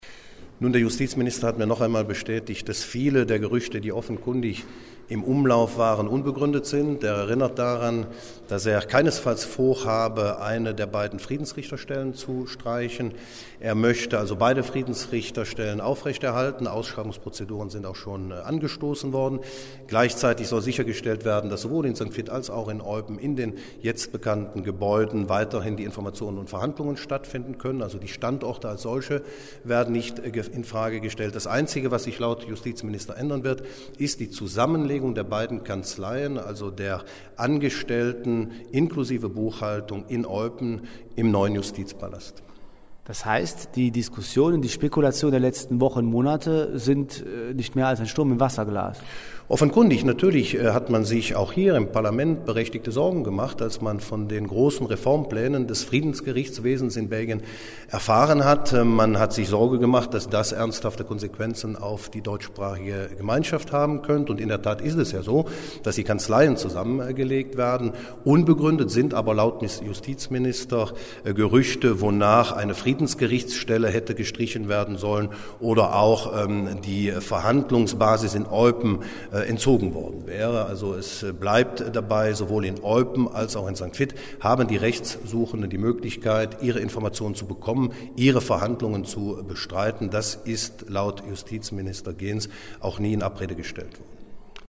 Dazu Ministerpräsident Oliver Paasch: